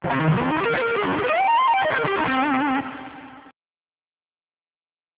Advanced Sweep Picking
Same idea but in a minor key